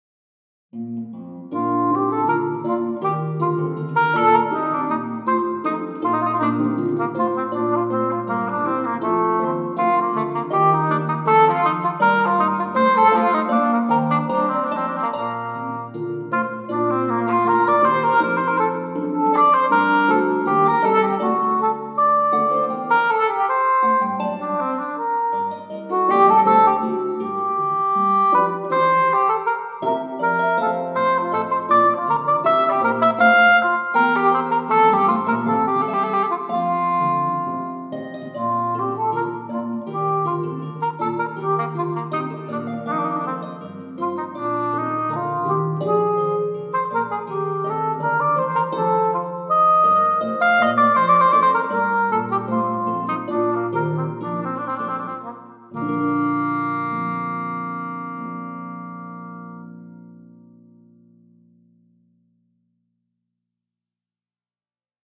all created with samples
Most of the pieces are played between 2002 and 2014 and also mixed as it was normal at that time...